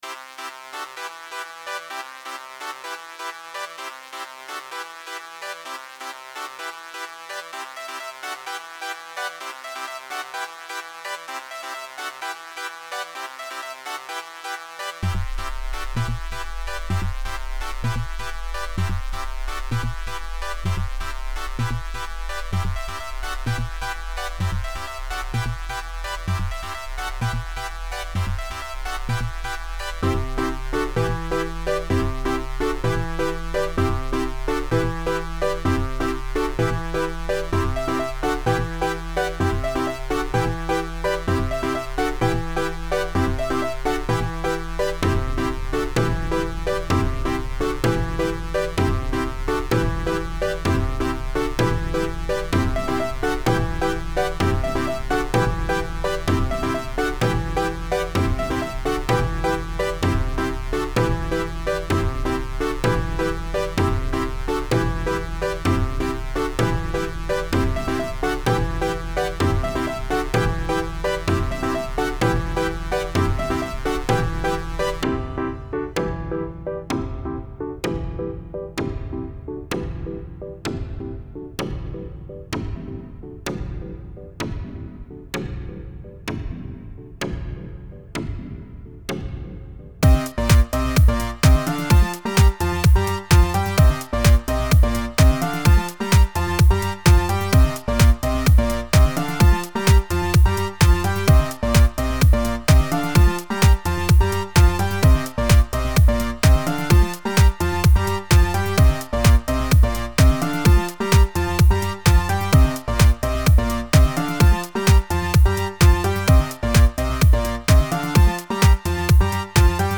Home > Music > Electronic > Bright > Running > Chasing